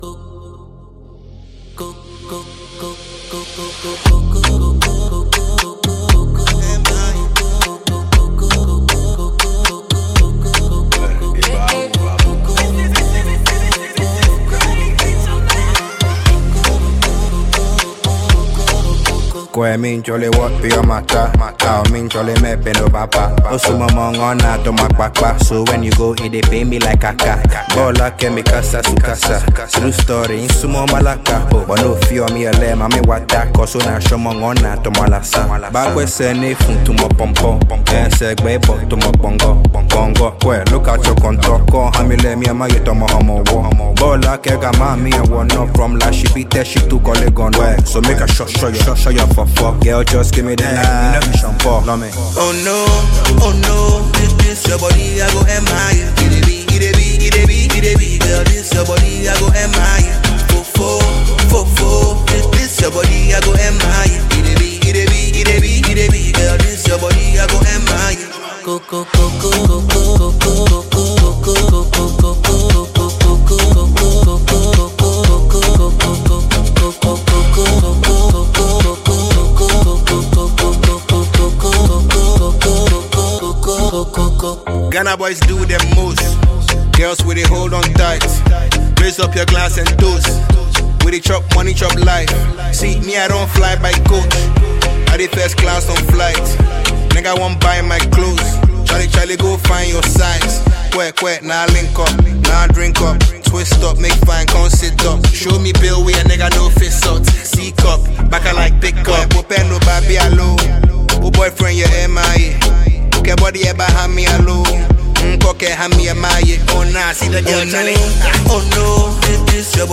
Award-winning rapper